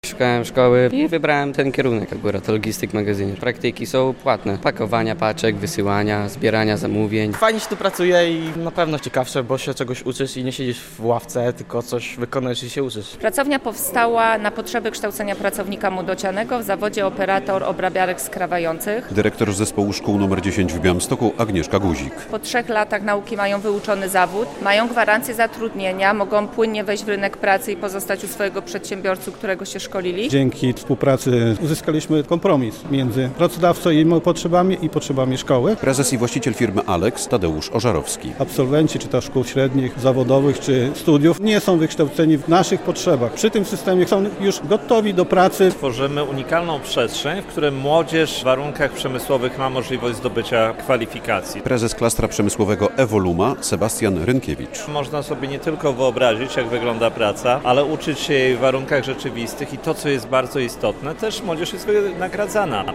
Otwarcie pracowni dydaktycznej dla uczniów szkoły branżowej w Białymstoku - relacja